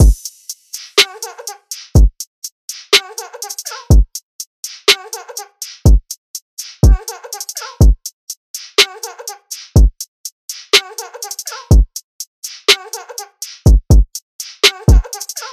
DRUM LOOPS
Nusery (123 BPM – Bbm)
UNISON_DRUMLOOP_Nusery-123-BPM-Bbm.mp3